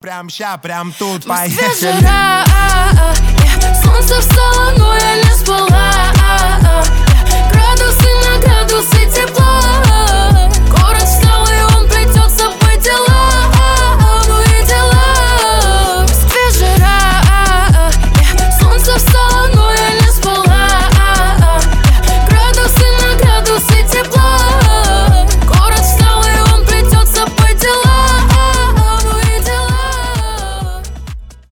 pop rap